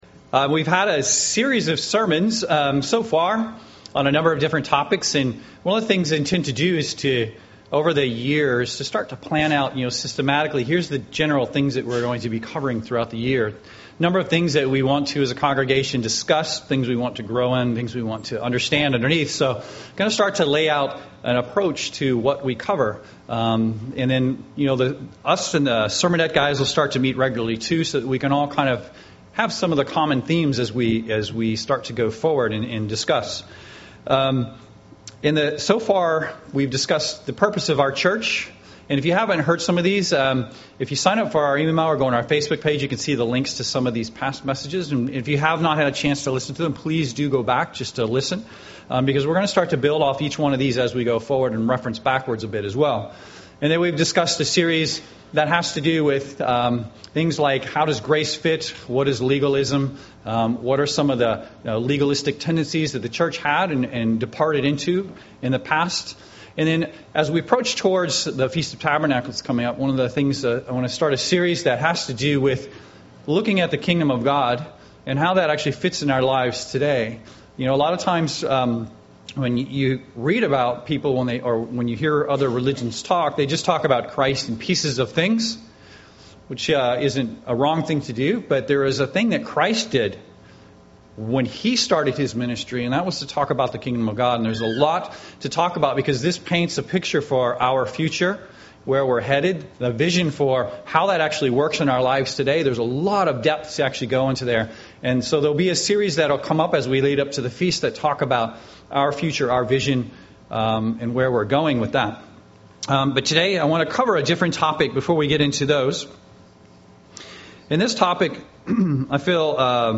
Print UCG Sermon